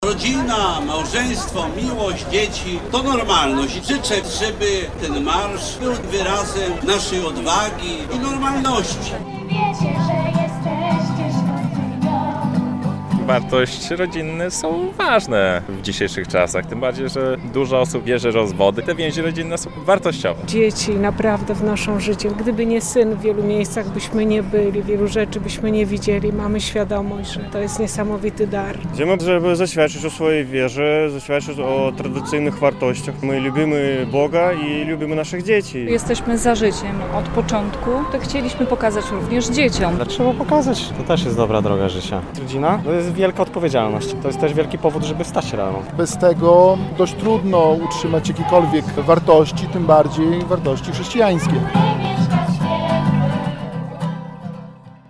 Marszu dla Życia w Białymstoku - relacja
Pochód - z transparentami i śpiewem - wyruszył sprzed katedry i przeszedł kilkoma ulicami w centrum miasta.